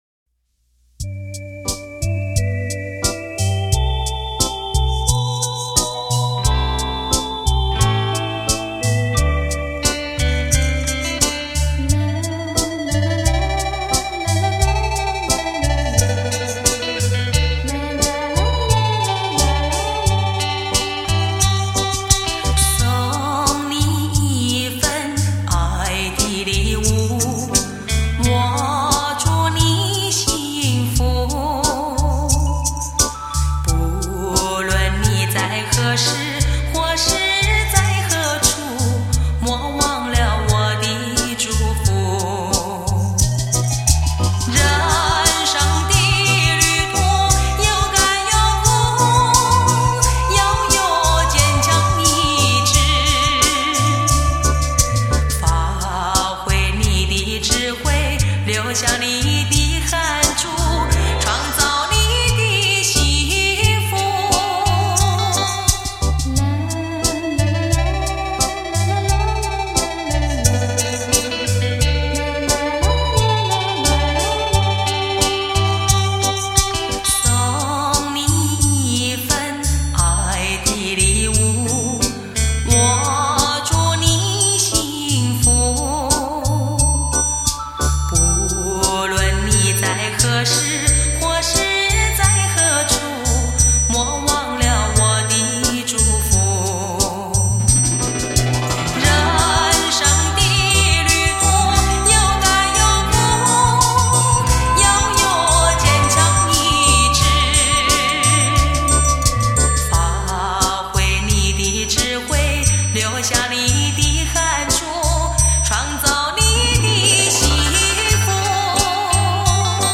百万双电子琴